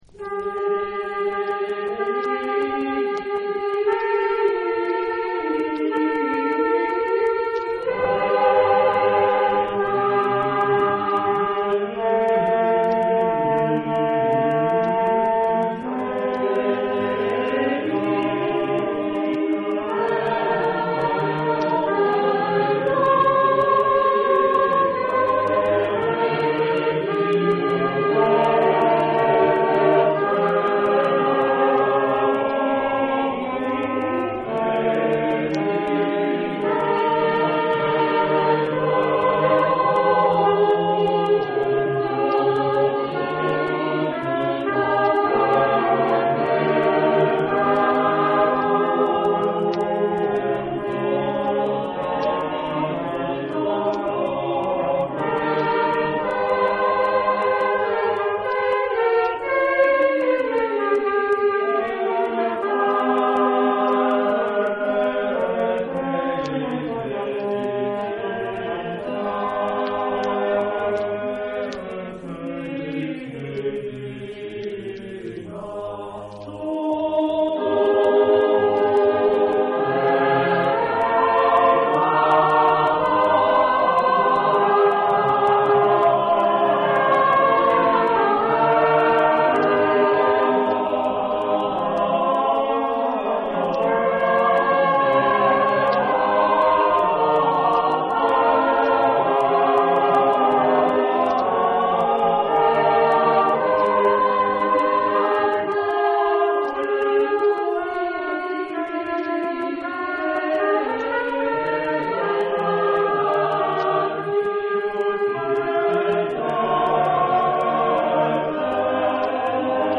Epoque: 15th century
Genre-Style-Form: Sacred ; Renaissance ; Motet
Type of Choir: ATTBBB OR SSATBB  (6 mixed voices )
Instruments: Trumpet (1) ; Trombone (1)
Tonality: C major